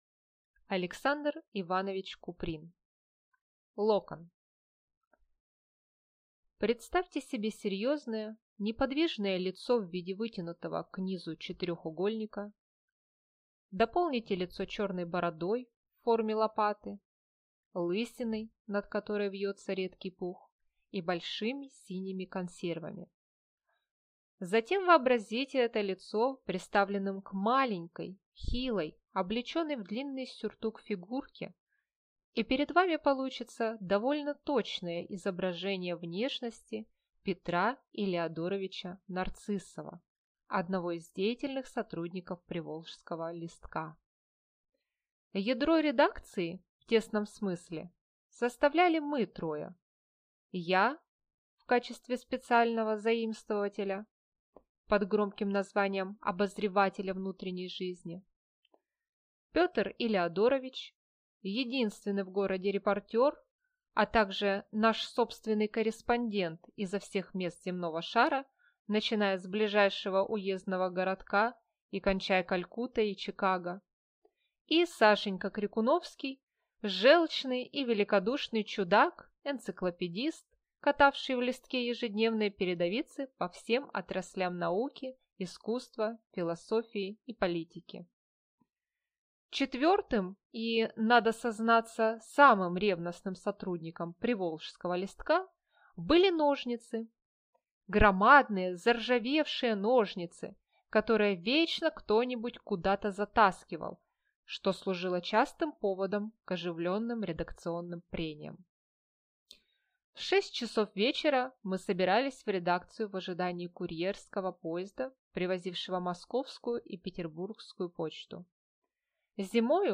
Аудиокнига Локон | Библиотека аудиокниг